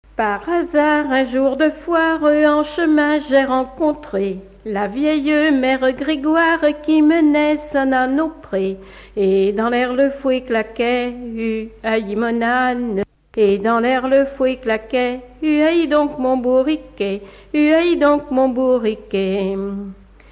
Mémoires et Patrimoines vivants - RaddO est une base de données d'archives iconographiques et sonores.
Genre laisse
Catégorie Pièce musicale inédite